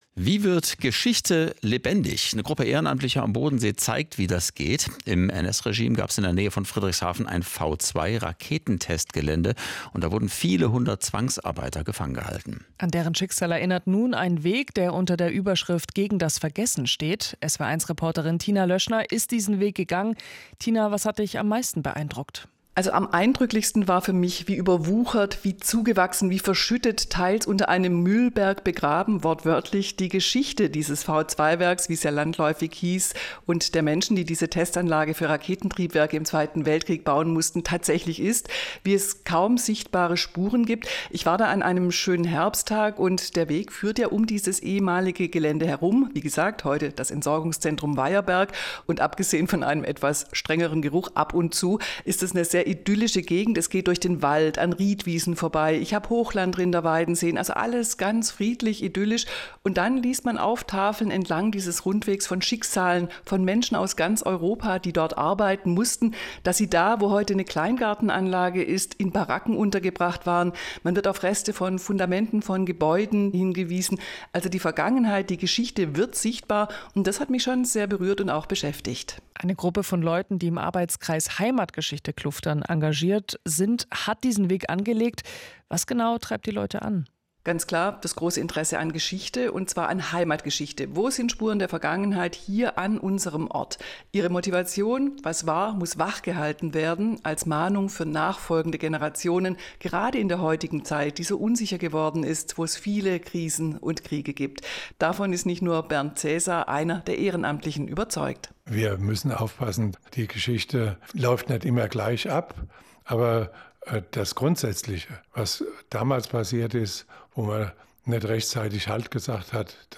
Die Anlage „BWM Talk Rundweg.MP3“ ist ein kurzer Mitschnitt (Länge nur 2:41 Minuten) zu diesem Projekt aus dem SWR-Radioprogramm.